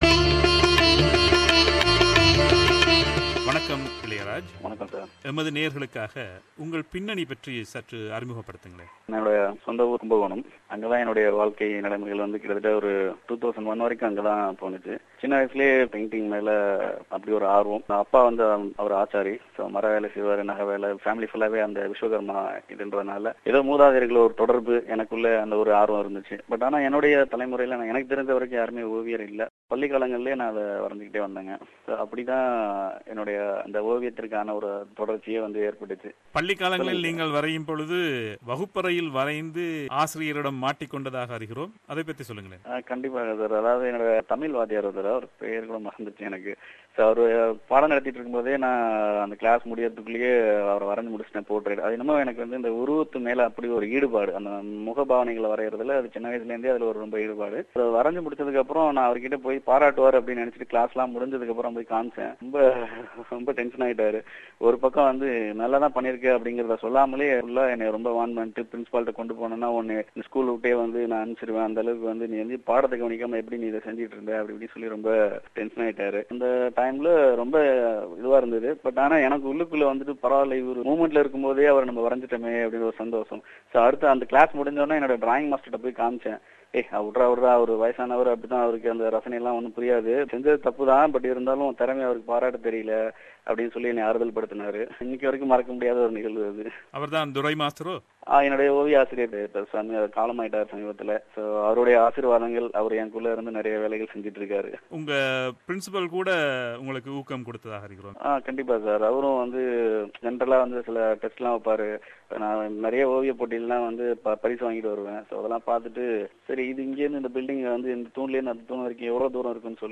இரண்டு பகுதிகளாக இணையத்தளமேறும் நேர்காணலின் முதற் பாகத்தில்